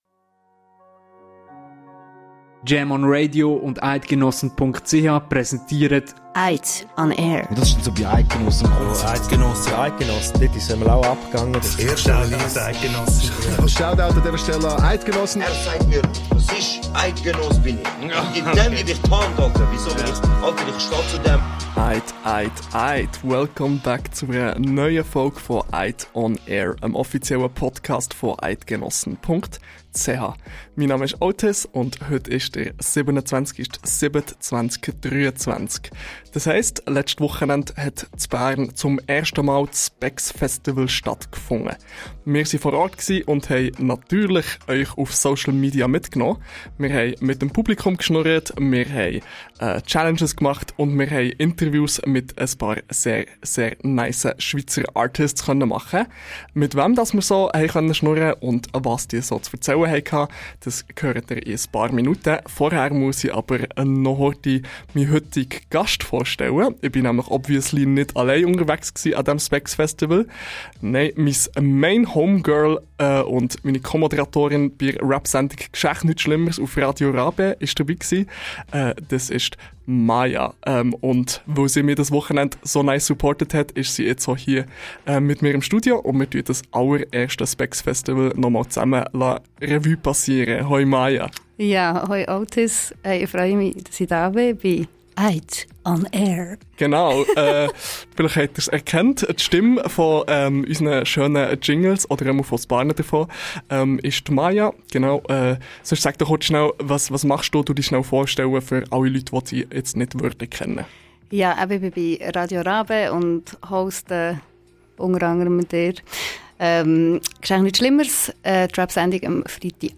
Beschreibung vor 2 Jahren Spex! So heisst das neuste Rap-Festival der Schweiz. Letztes Wochenende hat es zum ersten mal stattgefunden, und wir waren dabei!